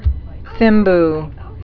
(thĭmb, tĭm-)